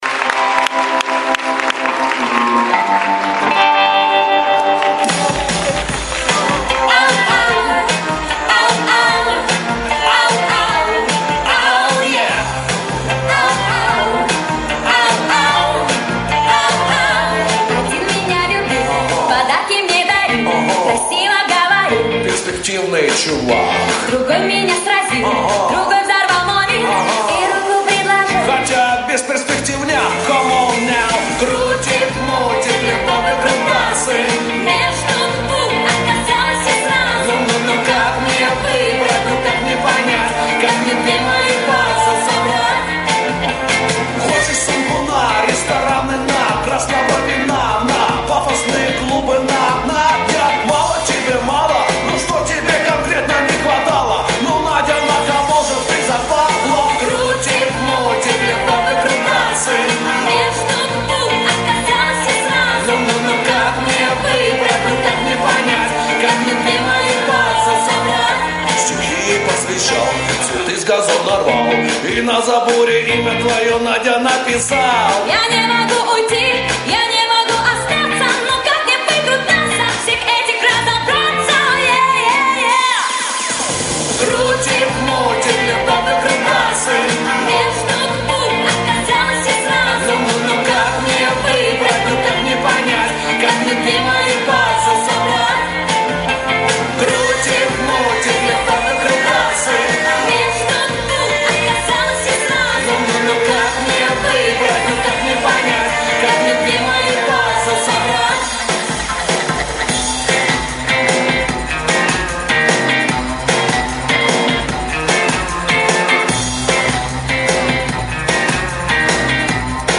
Категория: Поп Музыка